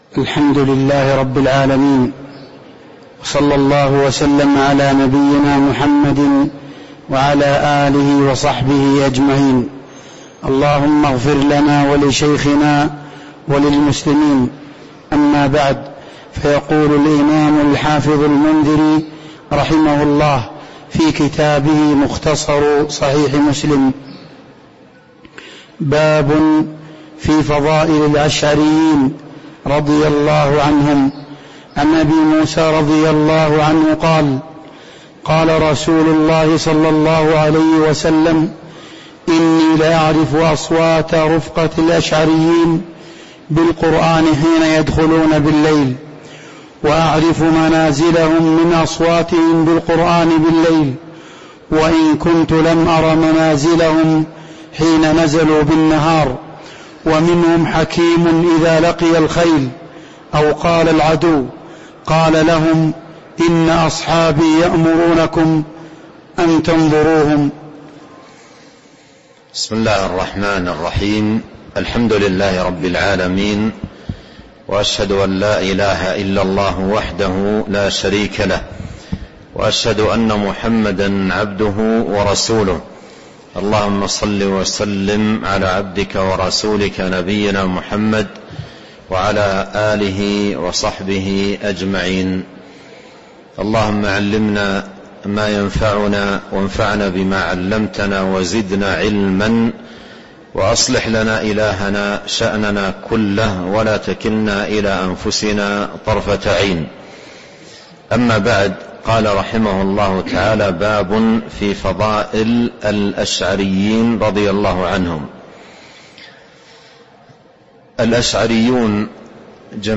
تاريخ النشر ١٩ رمضان ١٤٤٣ هـ المكان: المسجد النبوي الشيخ: فضيلة الشيخ عبد الرزاق بن عبد المحسن البدر فضيلة الشيخ عبد الرزاق بن عبد المحسن البدر باب في فضل الأشعريين رضي الله عنهم (026)